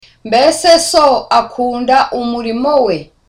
Dialogue: A conversation between Tereza and Sonita
(Relaxed)